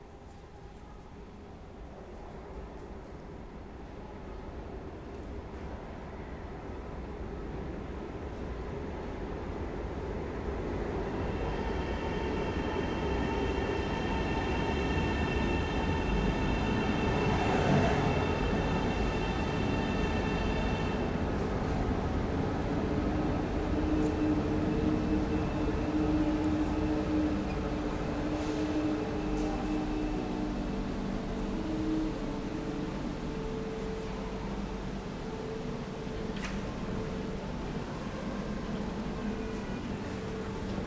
Arlanda train station, Stockholm